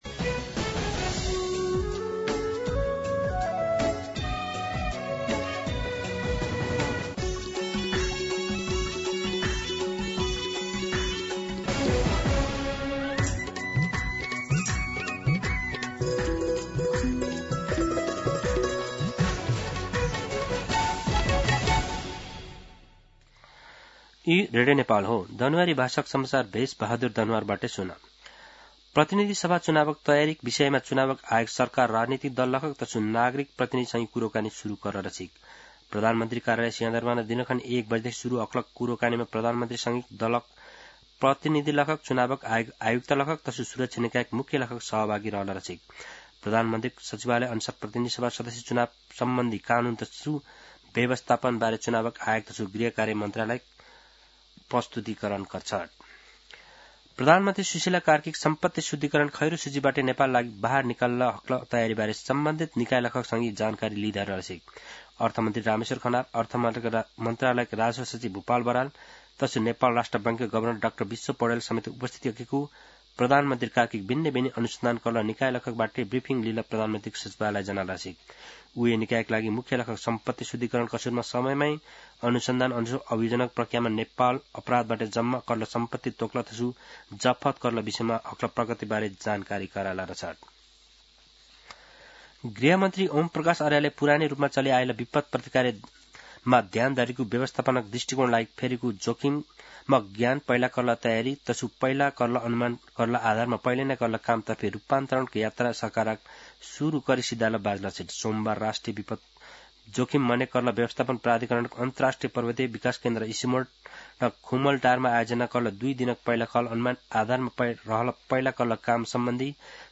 दनुवार भाषामा समाचार : ७ पुष , २०८२
Danuwar-News-9-7.mp3